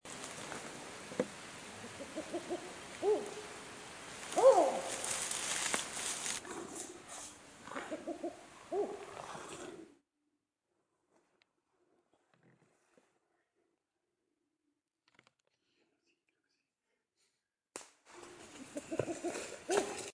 Black-banded Owl (Strix huhula)
Province / Department: Misiones
Location or protected area: Bio Reserva Karadya
Condition: Wild
Certainty: Photographed, Recorded vocal